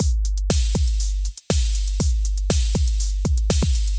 咚咚鼓
Tag: 120 bpm Hip Hop Loops Drum Loops 689.24 KB wav Key : Unknown